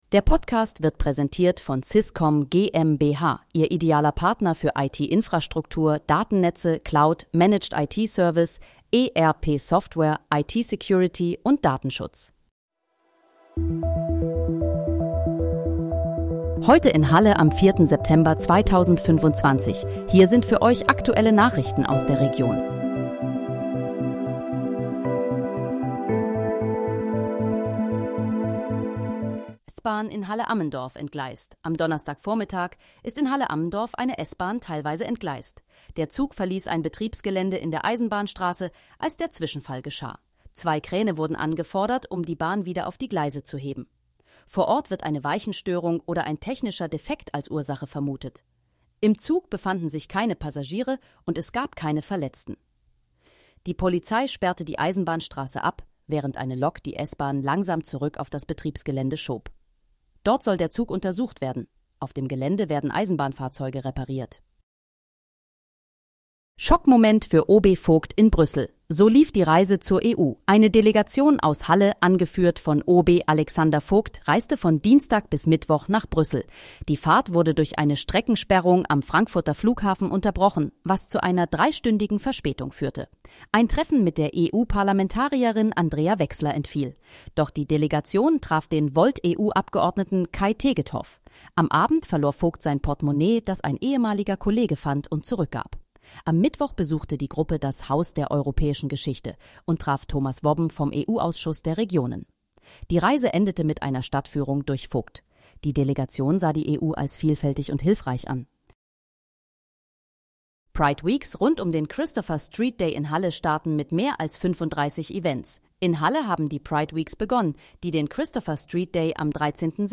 Heute in, Halle: Aktuelle Nachrichten vom 04.09.2025, erstellt mit KI-Unterstützung
Nachrichten